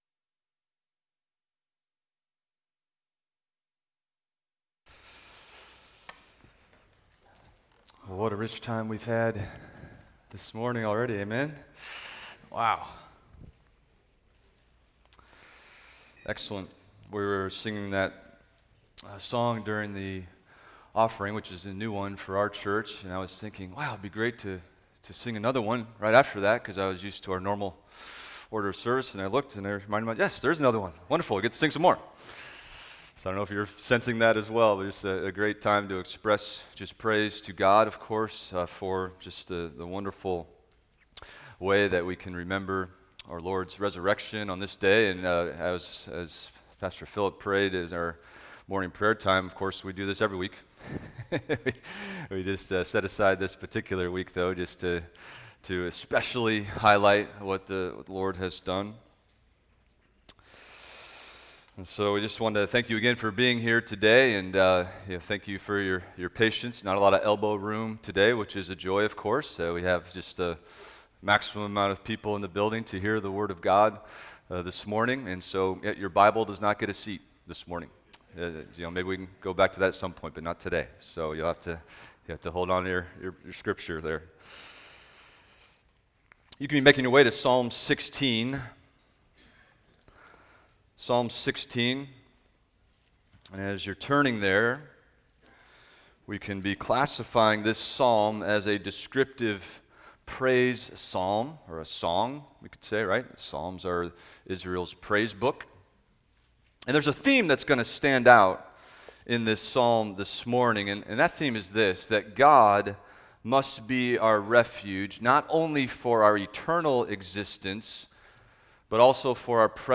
Expository Preaching from the Psalms – Psalm 16 - The Pathway to Having Confident Security in God (Resurrection Sunday)